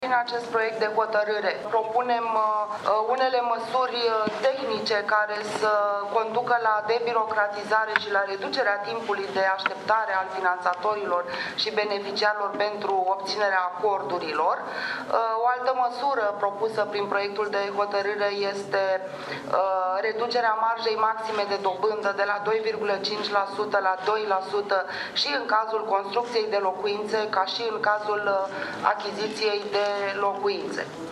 Daniela Pescaru, secretar de stat în ministerul de Finanțe, în timpul ședinței de Guvern de la Palatul Victoria: